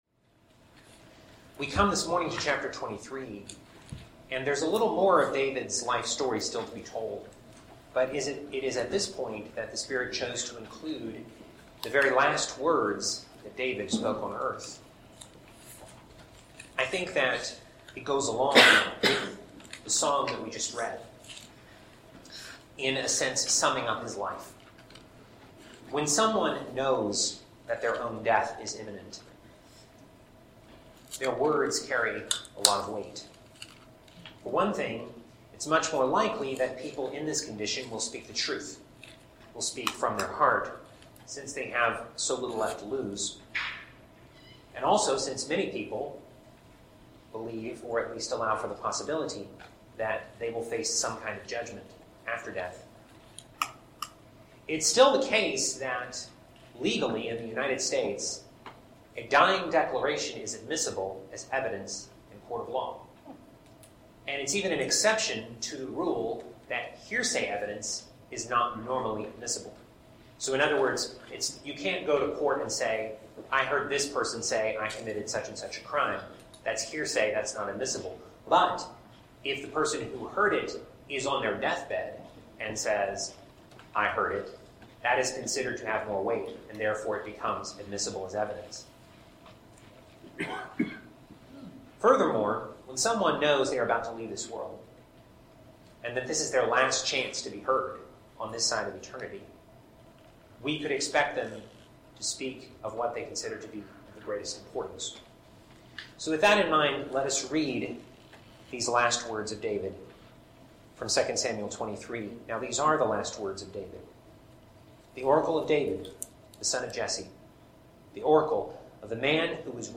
Teaching For March 16, 2025